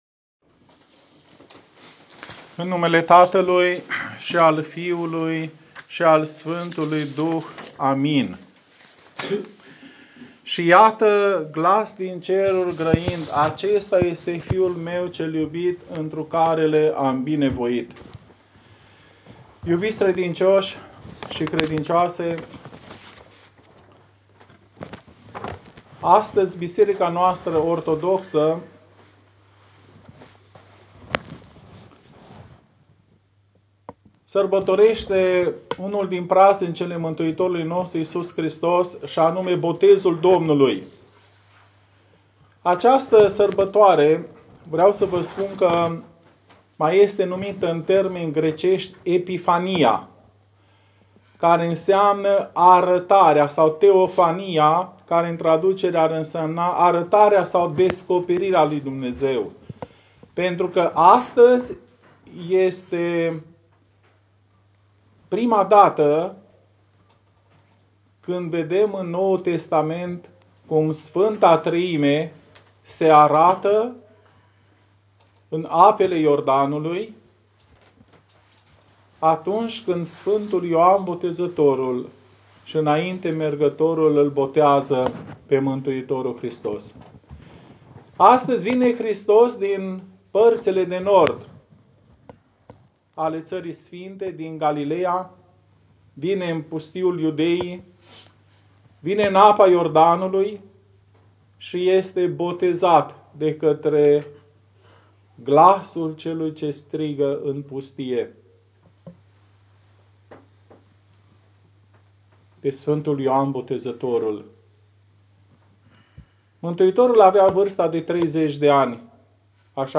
Predica a fost ținută în paraclisul Mănăstirii Bîrnova.
predica-despre-botez.m4a